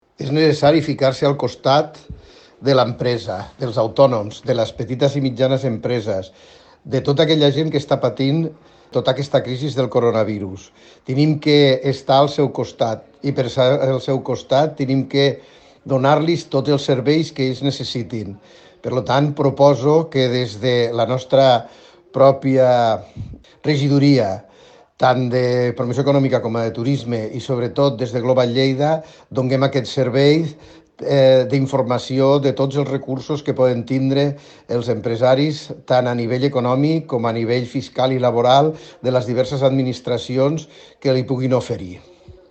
Tall de veu Paco Cerdà.
tall-de-veu-paco-cerda